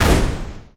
poly_explosion_fireball3.wav